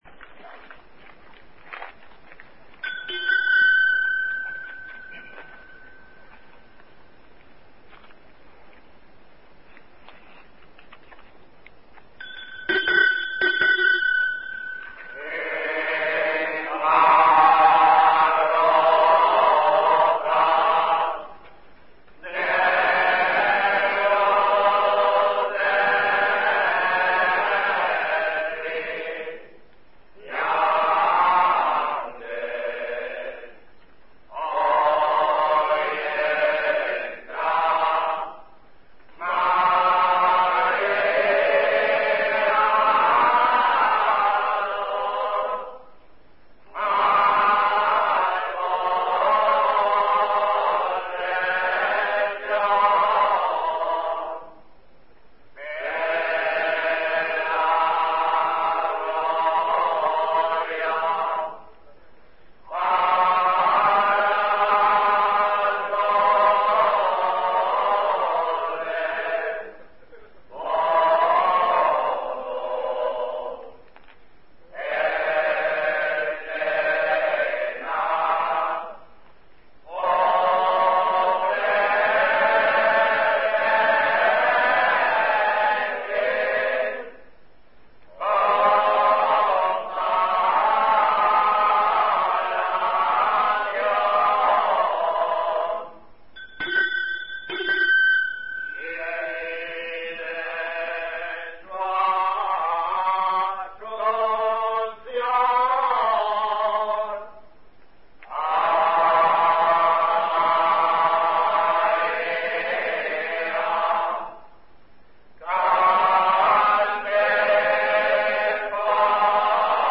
El canto de las AURORAS
Las auroras, los cantos religiosos que se entonan al alba y con el que da comienzo un día de celebración de una festividad religiosa, parecen tan consustanciales a Huesa del Común, como presente está su castillo en el horizonte de la localidad..
Los siguientes son los cantantes de auroras en Huesa del Común, en agosto de 2006
Los auroreros se detienen a cantar la aurora en varios cruces estratégicos de la localidad turolense de tal forma que lleguen al máximo de fieles y vecinos.